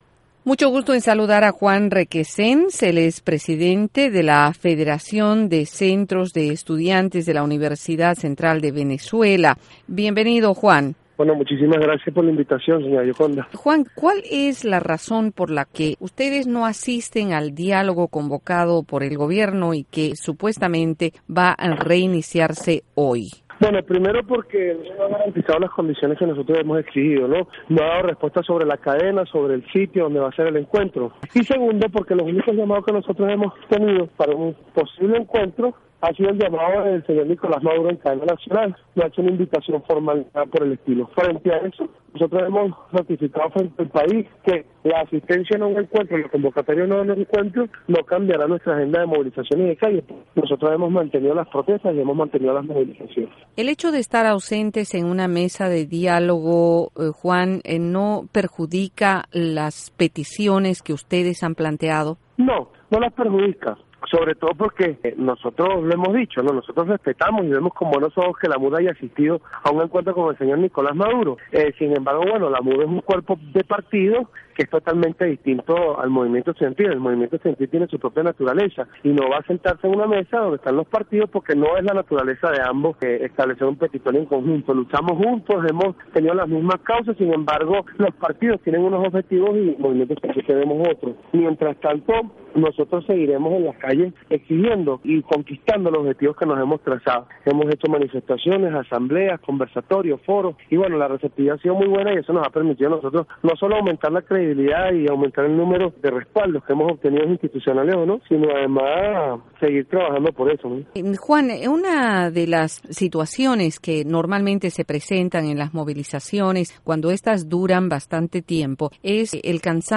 Insertar share Entrevista Juan Requesens - UCV Por Voz de América Insertar share El código se ha copiado en su portapapeles.